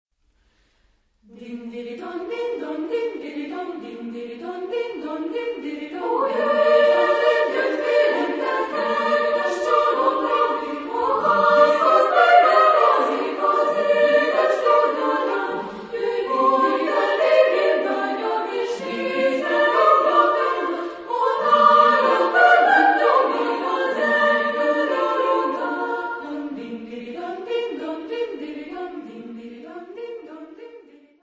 Género/Estilo/Forma: Serenata
Carácter de la pieza : allegretto
Tipo de formación coral: SSA  (3 voces Coro femenino )
Instrumentos: Gran casa (ad lib)
Tonalidad : si bemol mayor